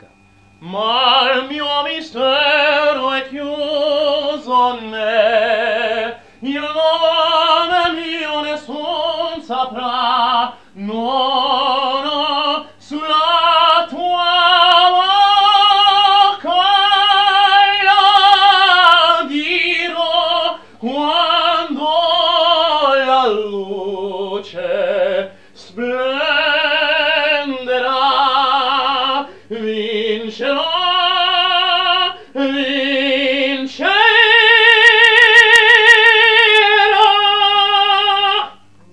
a cappella Puccini.